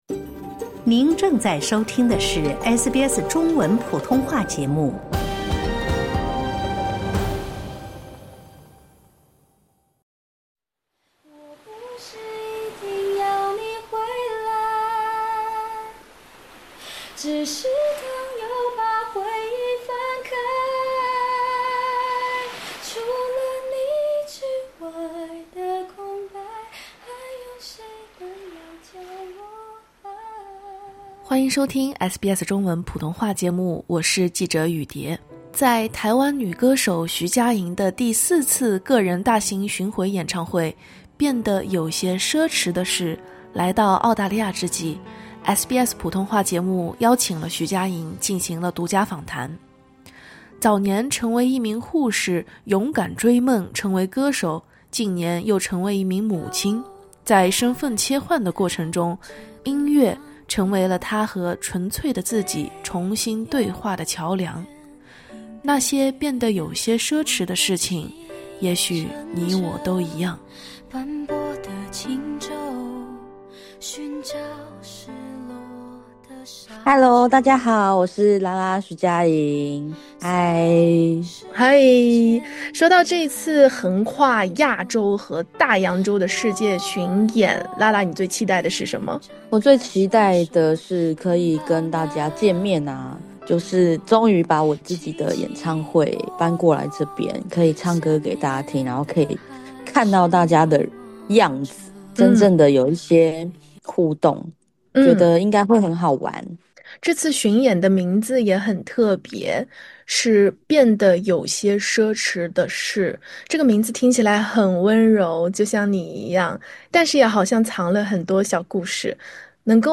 【专访】歌手徐佳莹：那些变得有些奢侈的事
在台湾女歌手徐佳莹的第四次个人大型巡回演唱会——“变得有些奢侈的事”来到澳大利亚之际，SBS普通话节目邀请了徐佳莹进行独家访谈。